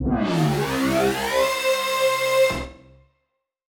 Index of /musicradar/future-rave-samples/Siren-Horn Type Hits/Ramp Up